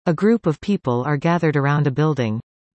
Finally, I converted the captions to speech by passing them through Google’s WaveNet (text-to-speech algorithm) available on the Google Cloud Platform, using Python’s text to speech library.